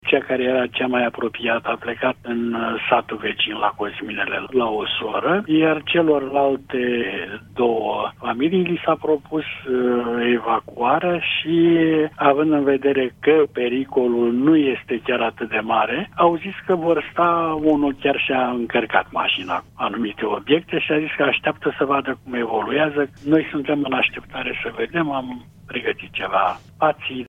04mai-18-Primar-despre-alunecari-de-teren-familii-evacuate.mp3